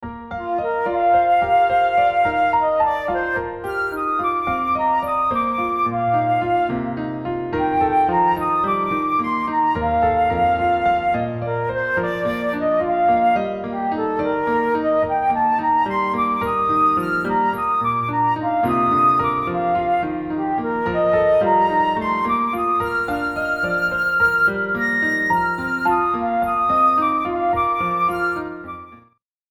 für Flöte und Klavier
Beschreibung:Klassik; Kammermusik
Besetzung:Flöte, Klavier